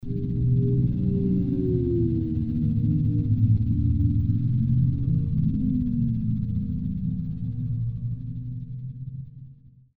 truck_moving.wav